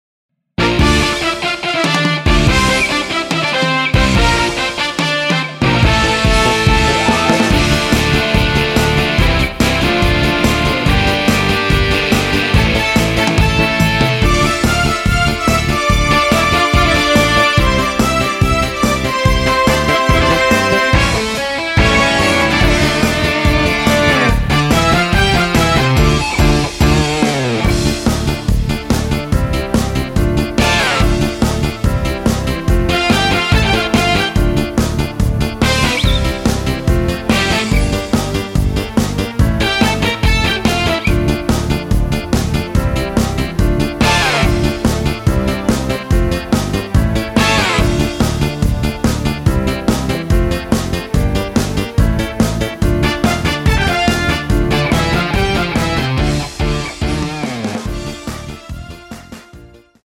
Dm
◈ 곡명 옆 (-1)은 반음 내림, (+1)은 반음 올림 입니다.
앞부분30초, 뒷부분30초씩 편집해서 올려 드리고 있습니다.
중간에 음이 끈어지고 다시 나오는 이유는